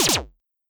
フリー効果音：被ダメージ
フリー効果音｜ジャンル：システム、ダメージや攻撃を受けたときのゲーム的効果音です！
damaged.mp3